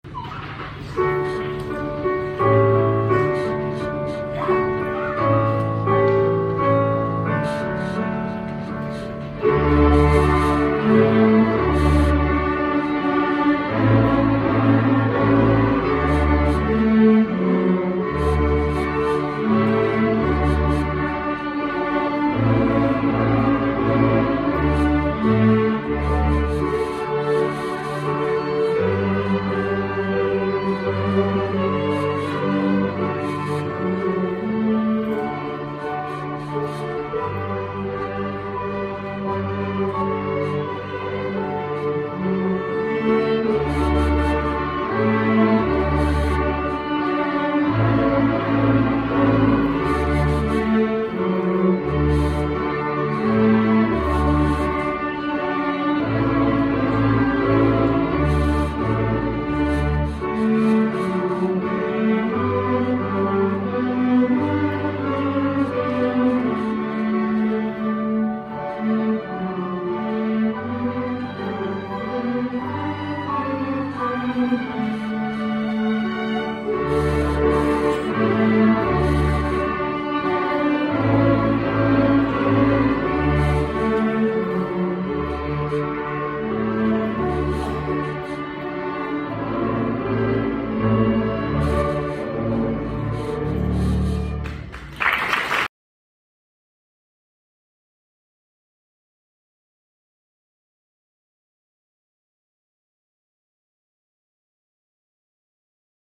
Boysie | Larch String Orchestra